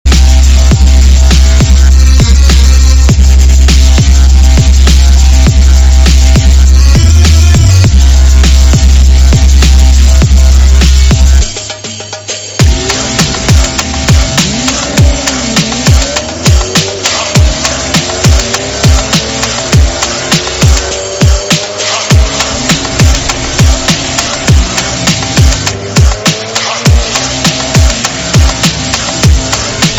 Arabic trap VFM 7
arabic-trap-vfm-7.mp3